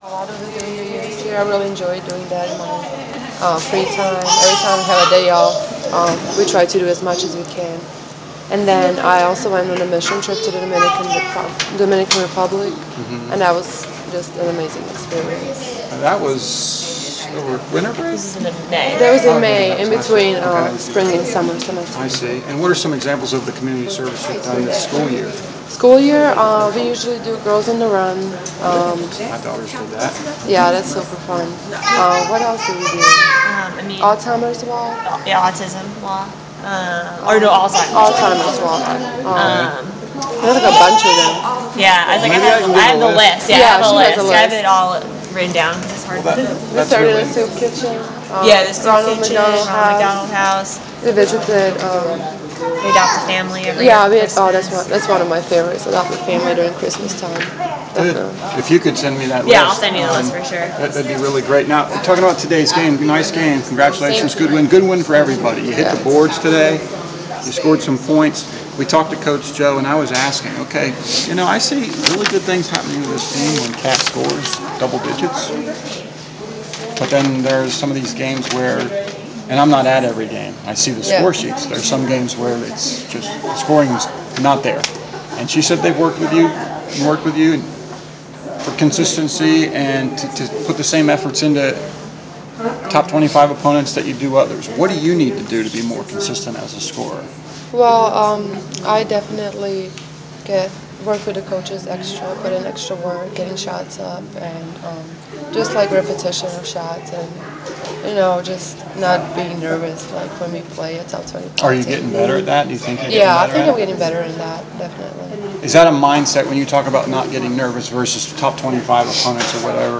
post-game presser